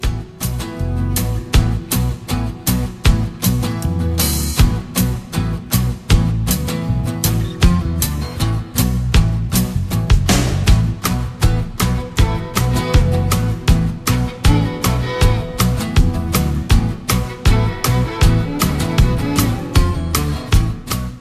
• Качество: 320, Stereo
гитара
саундтреки
без слов
инструментальные
виолончель